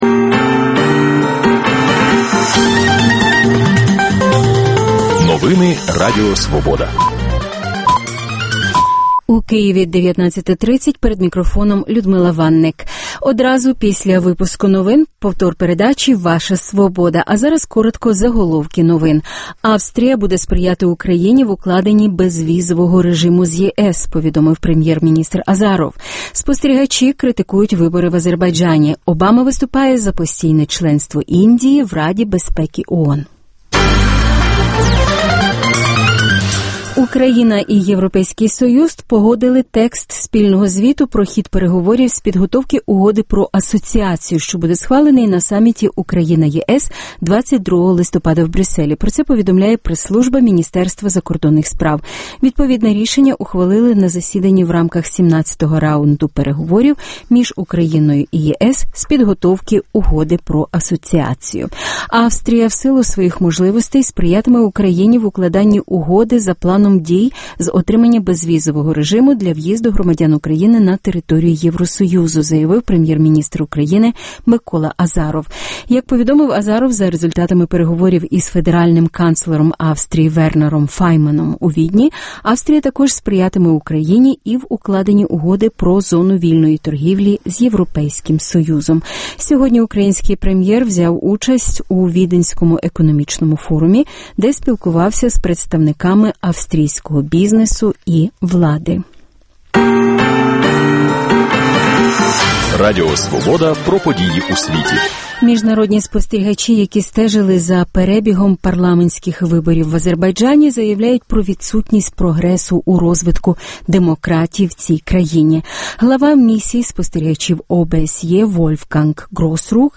Дискусія про головну подію дня. (Повтор денного випуску)